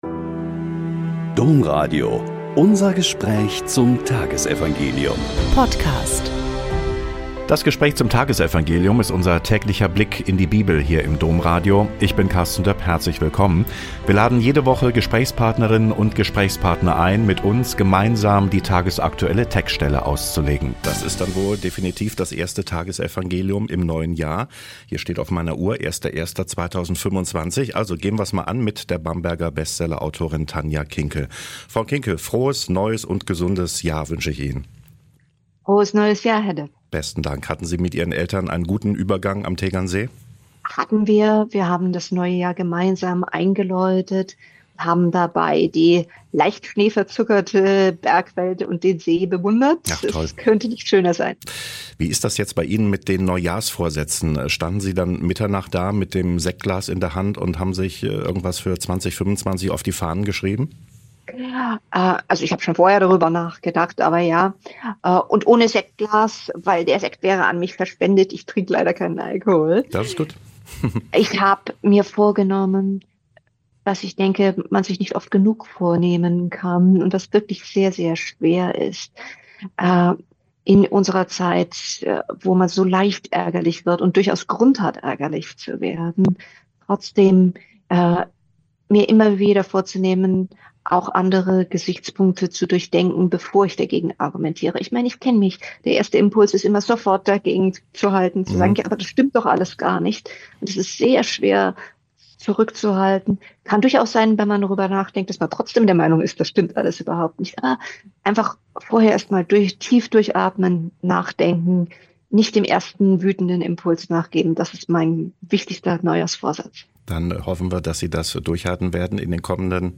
Lk 2,16-21 - Gespräch mit Dr. Tanja Kinkel